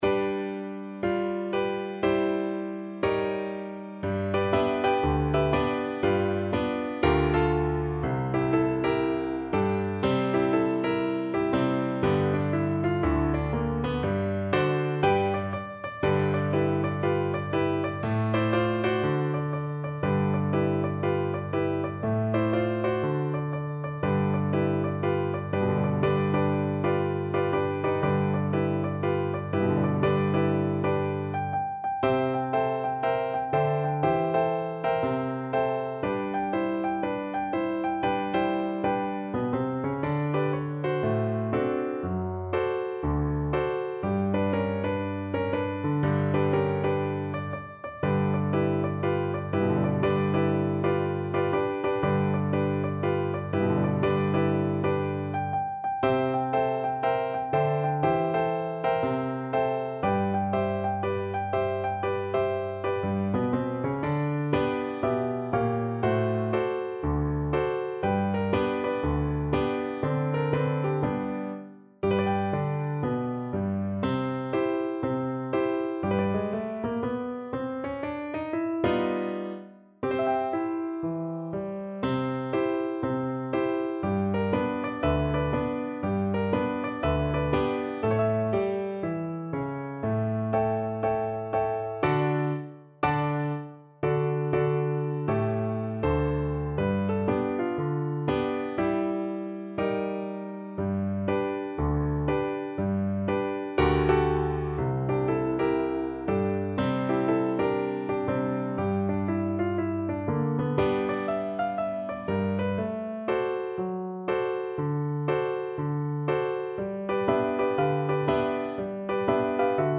Jazz (View more Jazz Viola Music)